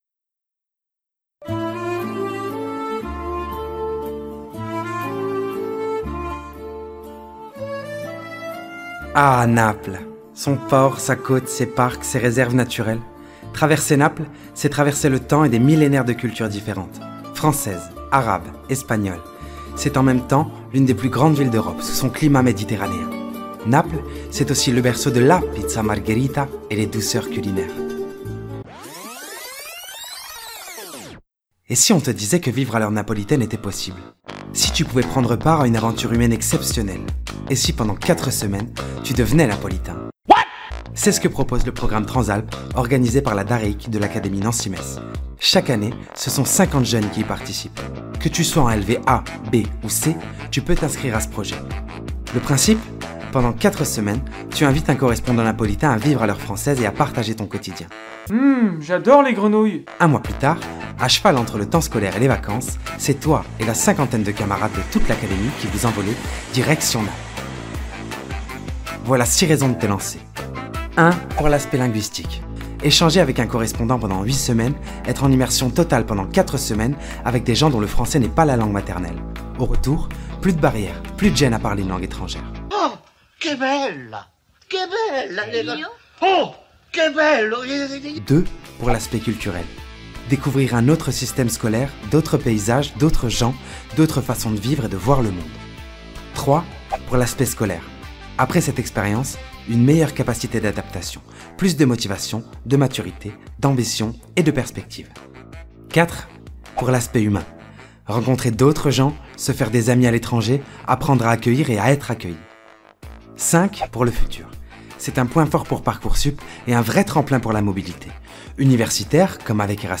Voix off
Spot promotionnel institutionnel - Trans'Alp - APIL/DAREIC
25 - 40 ans - Baryton